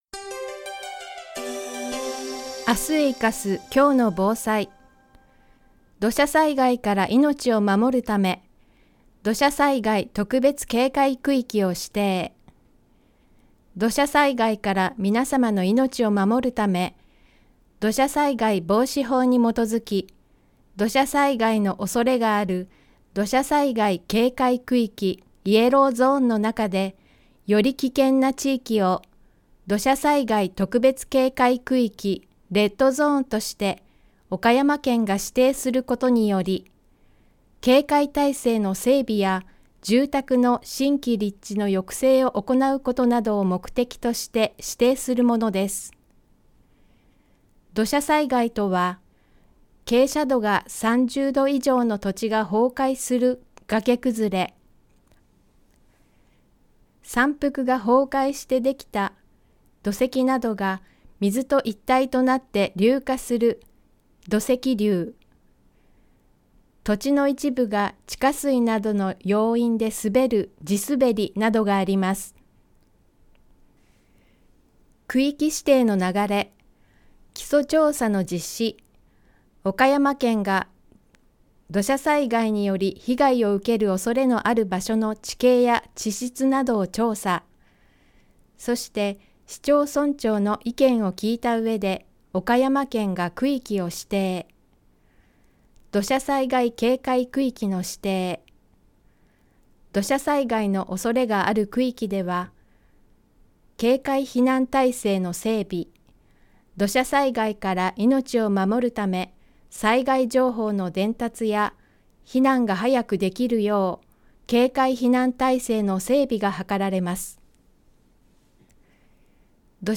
2020年声の広報みさき12月号 声の広報 広報誌の一部を読み上げています。